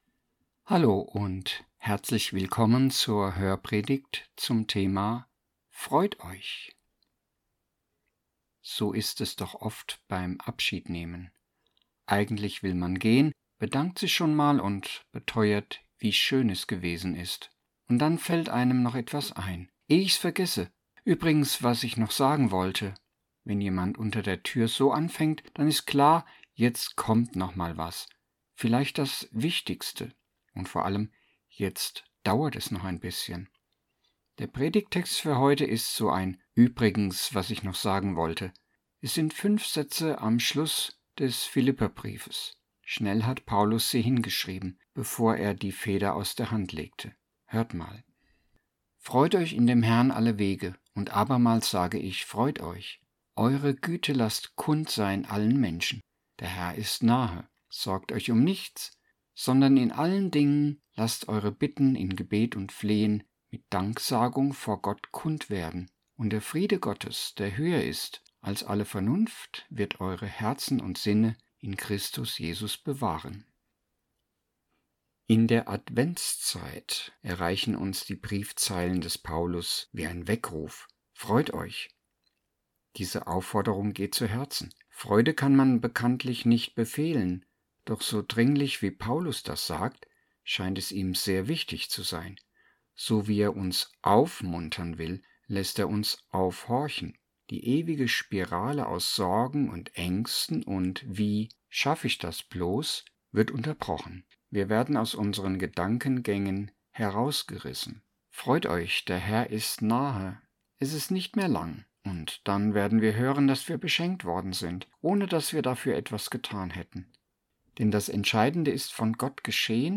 Hörpredigt-ZV-Dezember_und_Advent_2024.mp3